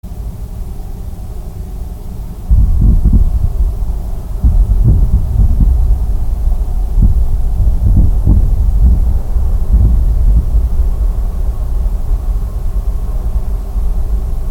Heute morgen gegen 04:48 war über Brandenburg das Verglühen von Teilen der Raketenstufe einer Falcon 9 zu beobachten. Das Schauspiel konnte man aber auch mit den Ohren wahrnehmen, auch wenn es sich nur wie fernes Donnergrollen angehört hat. Meine Mikrofone haben es aufgezeichnet.